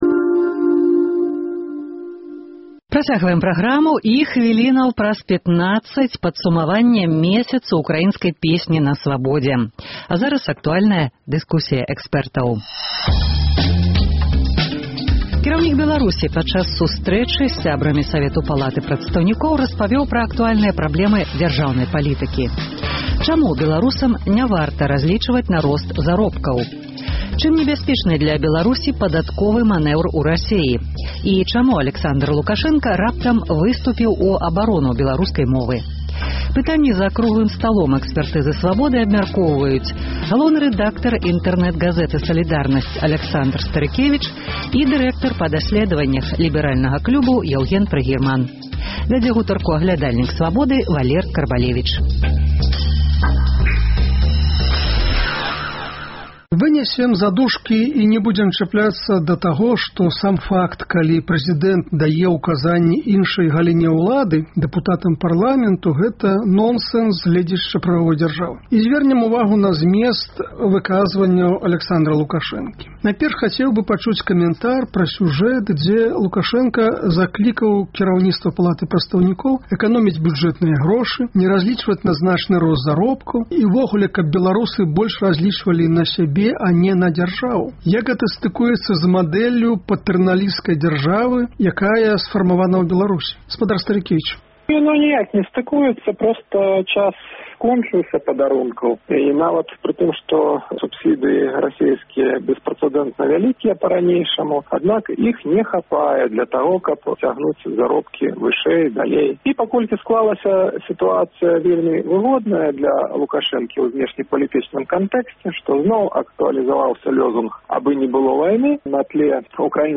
Гэтыя пытаньні абмяркоўваюць за круглым сталом "Экспэртызы "Свабоды”